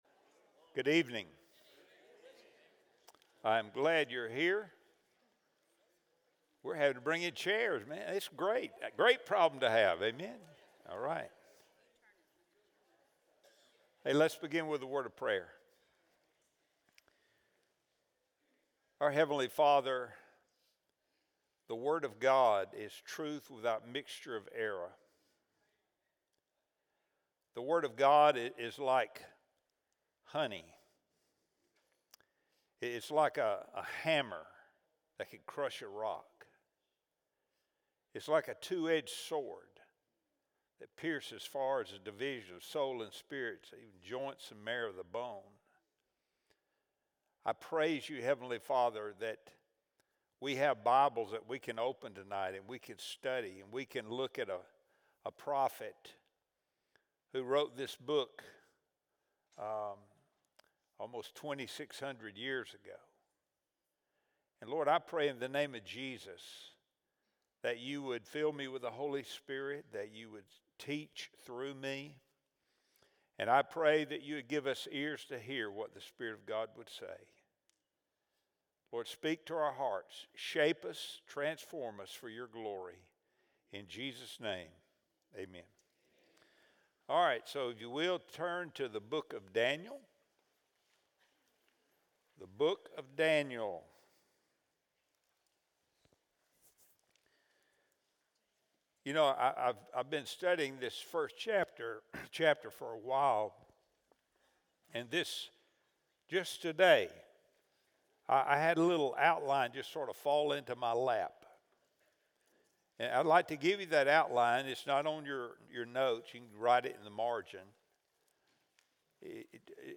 Wednesday Bible Study | September 3, 2025